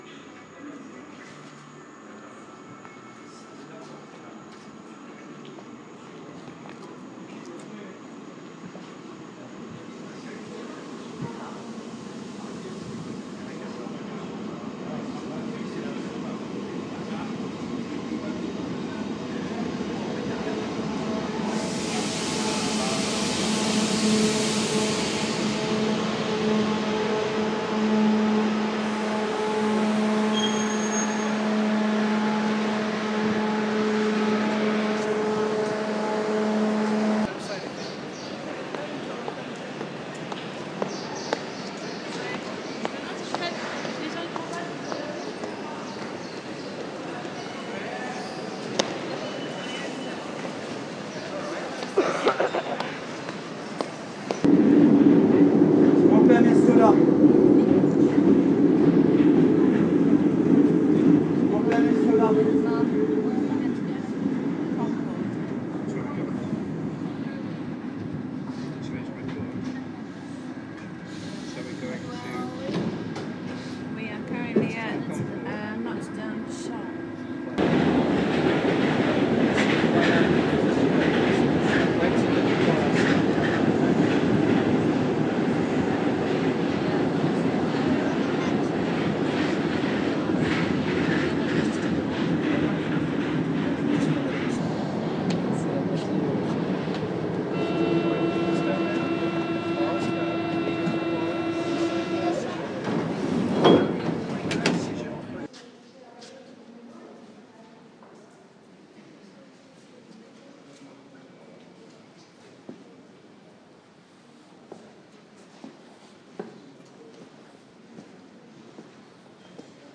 Paris ambient